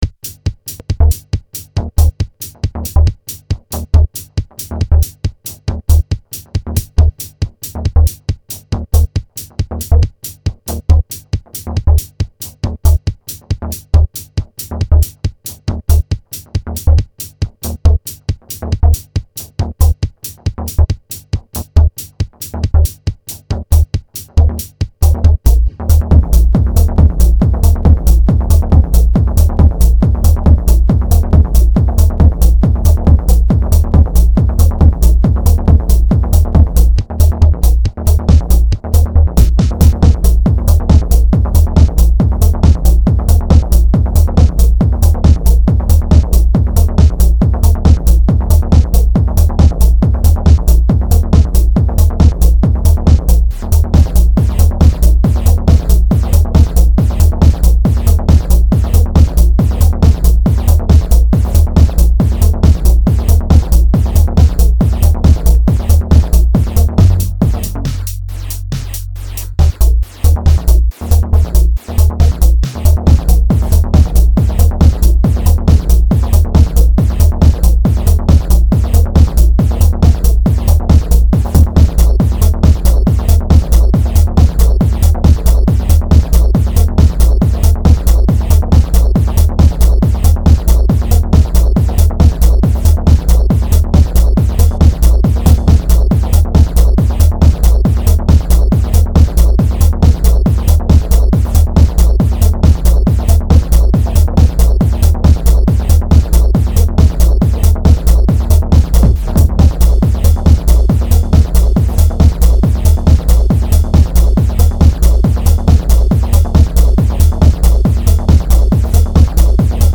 Unreleased minimal techno track
138 BPM